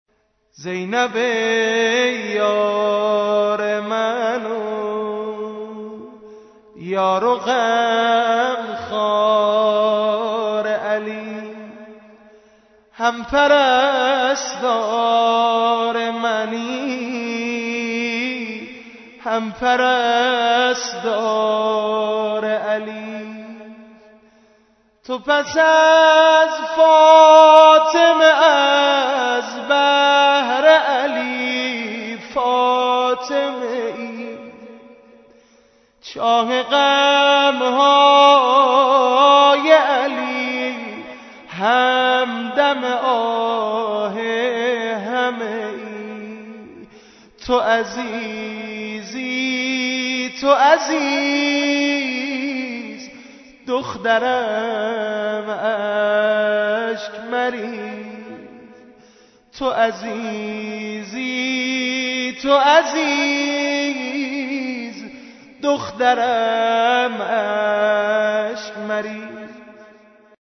بدون نغمه